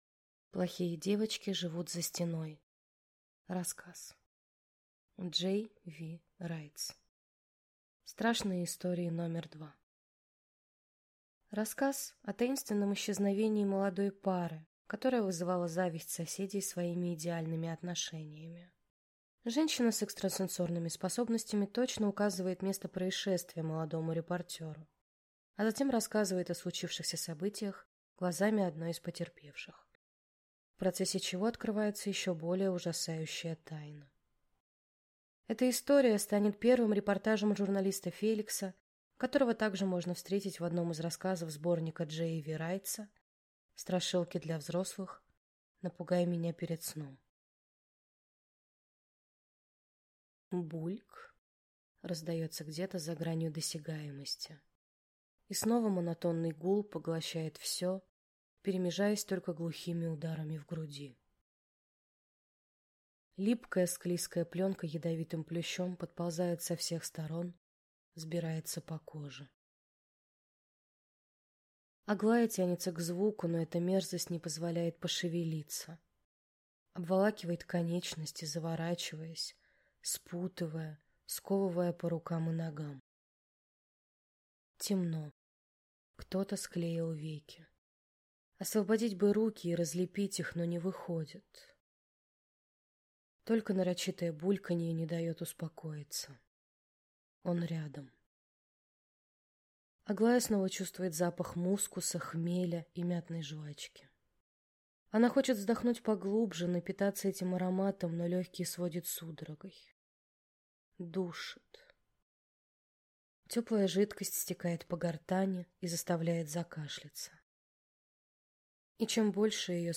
Аудиокнига Плохие девочки живут за стеной | Библиотека аудиокниг